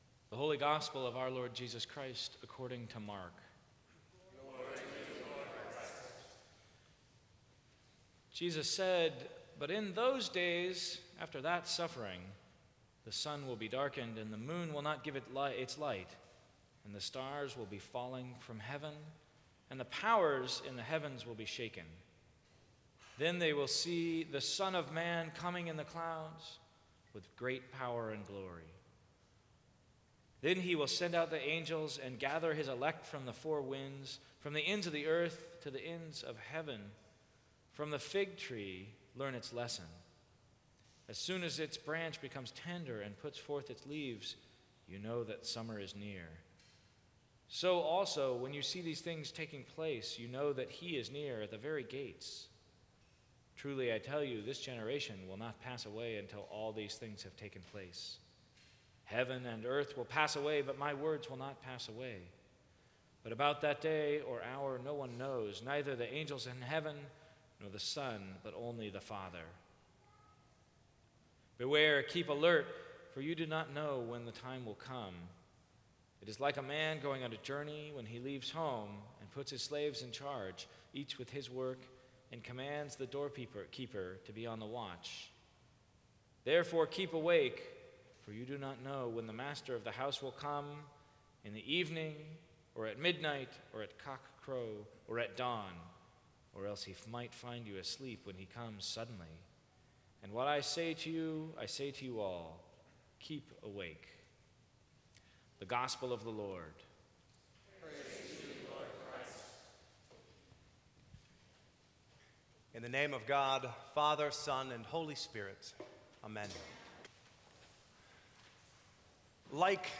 Sermons from St. Cross Episcopal Church November 30, 2014.